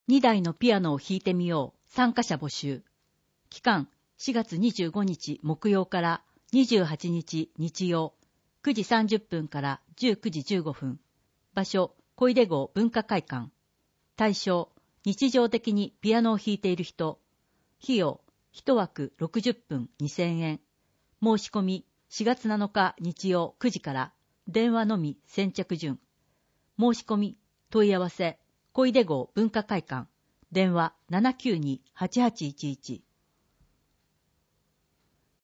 文字を読むことが困難な視覚障害者や高齢者や、市報を聞きたい方のために、「魚沼音声訳の会」のご協力により市報うおぬま音声版（ＭＰ3）をお届けします。